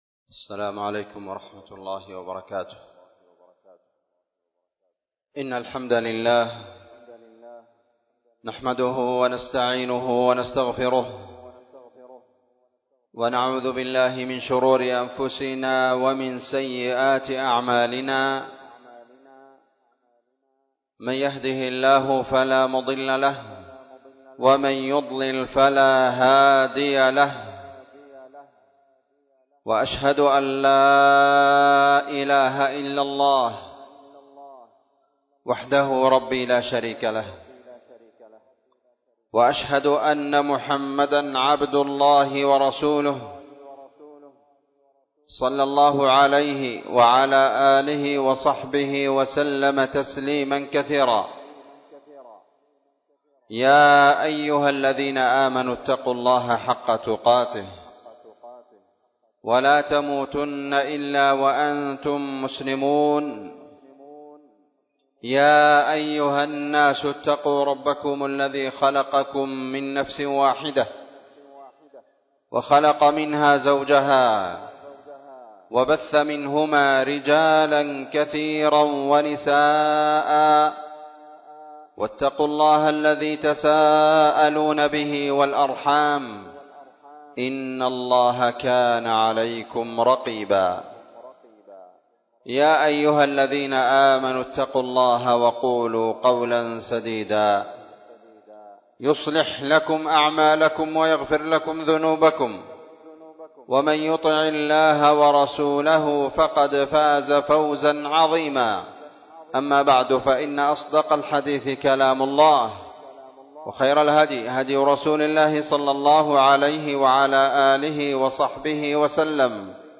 خطبة جمعة
مسجد دار الحديث- الضالع